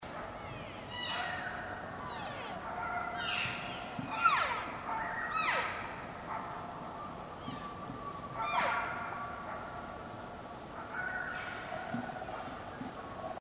On this page you now have access to actual recordings of live elk in the wild.
The elk recordings are grouped into loose categories for ease of use, but some sound bytes have both cows and bulls in them, as well as other animals, like coyotes, woodpeckers, squirrels, etc. The recordings are high quality, so the sounds of the forest are in the background, like the sound of creeks flowing.
Mature cow elk often have a raspy ending to their mews.
cow_mew_loud2.mp3